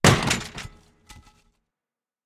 ladder-break.ogg